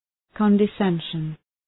{,kɒndı’senʃən}